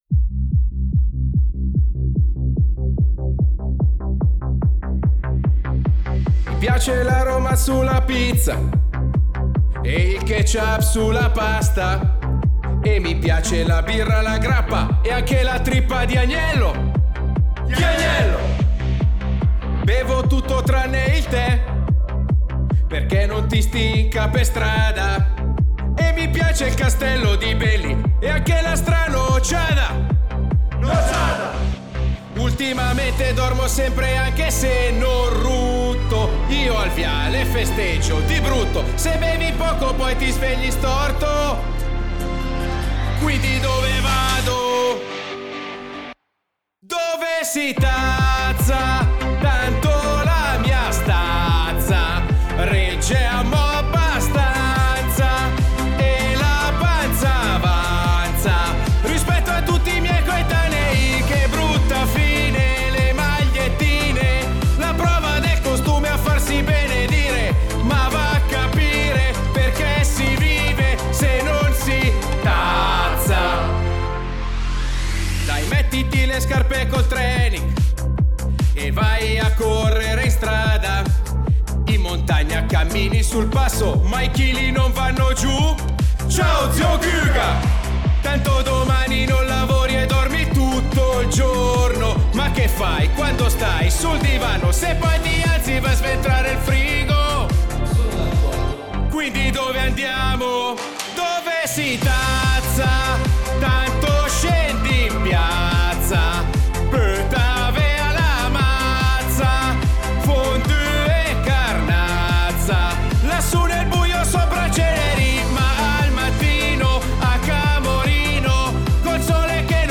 La parodia